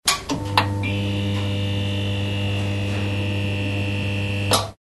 Шум работающей неоновой лампы